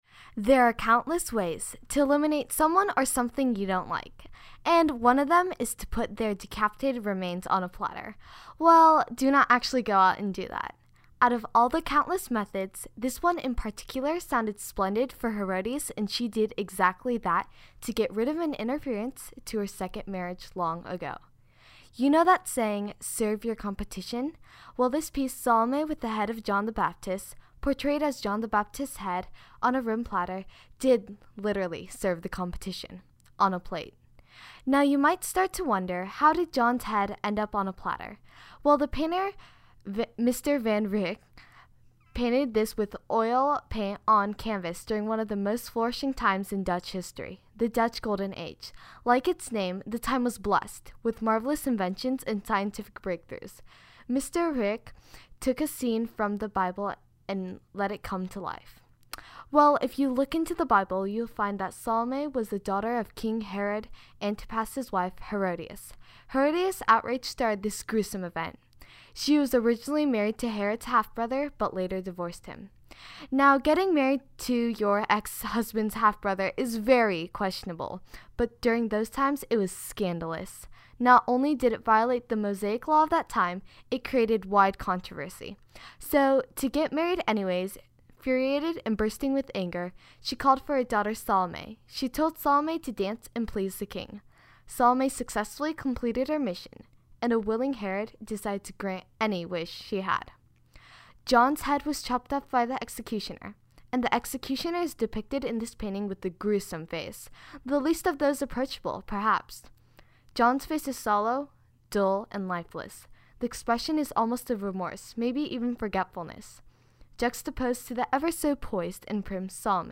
Audio Tour – Bulldog Art Tour